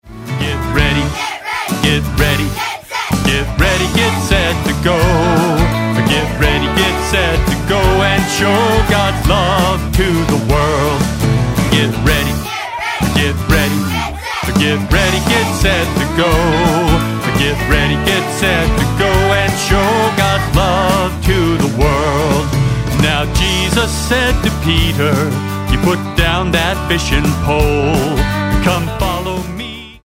Catholic hymns and songs